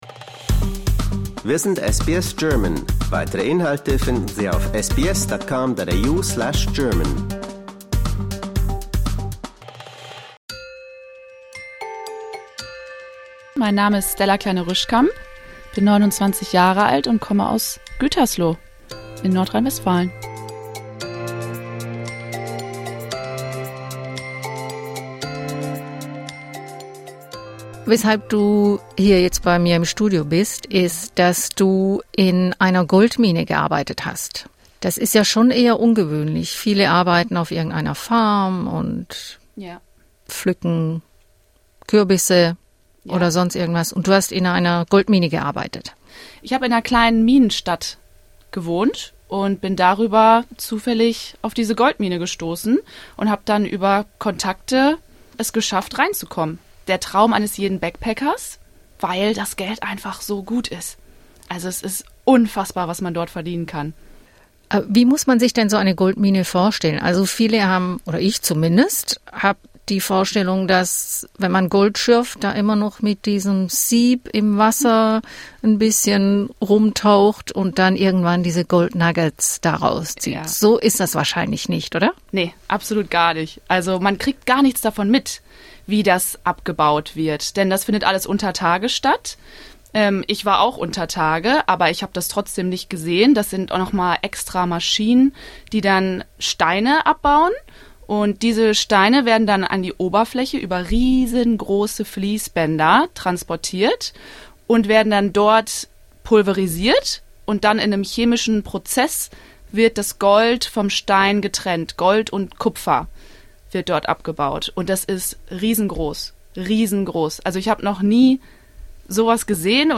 Wie es ist, als junge Frau in dieser staubigen und lauten Männer-Domäne zu arbeiten, erzählt sie im Interview.